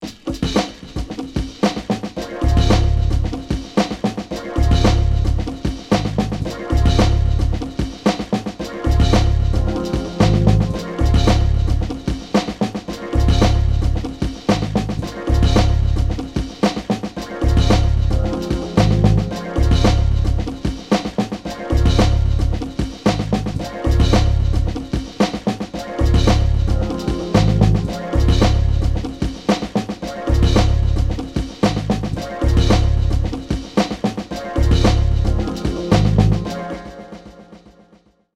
Steeper learning curve on the TV than I was expecting vs my experiences on DT/DN so all I managed is this rough loop.